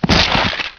thud.wav